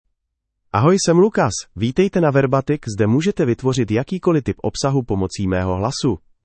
LucasMale Czech AI voice
Lucas is a male AI voice for Czech (Czech Republic).
Voice sample
Listen to Lucas's male Czech voice.
Male